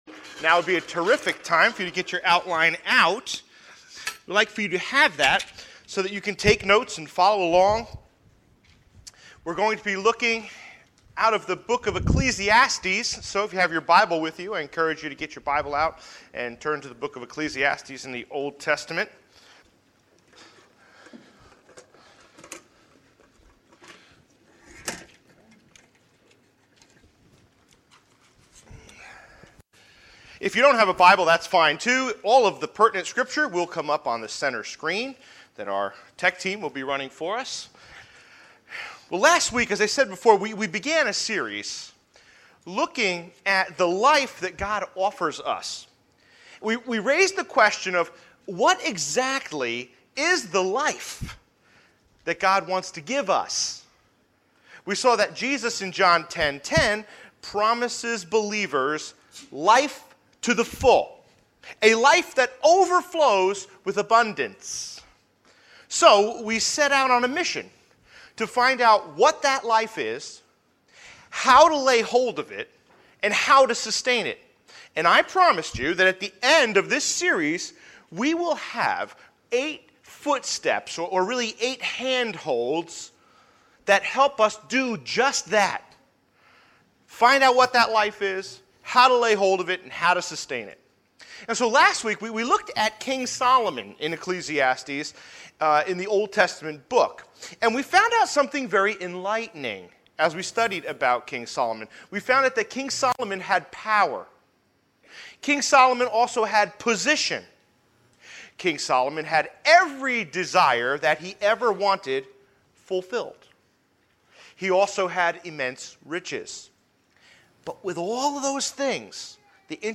Sermon Outline Audio Podcast